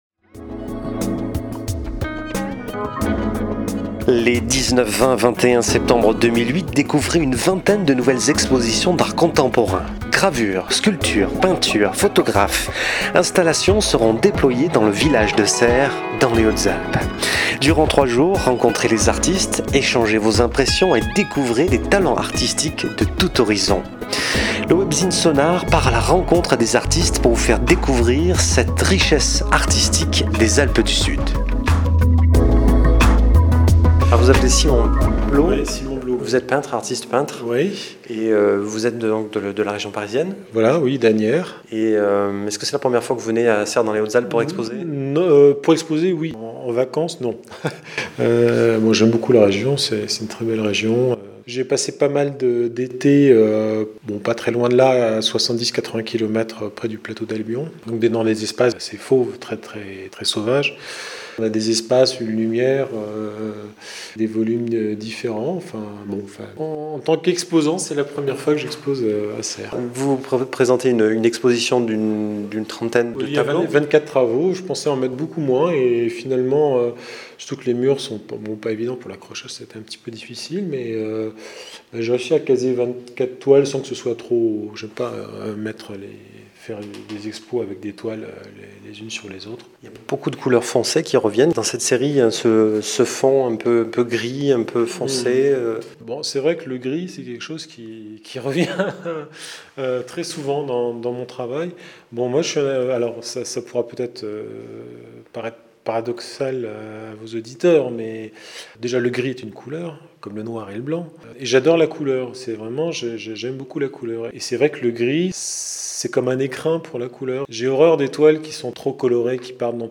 document sonore interview
interview.mp3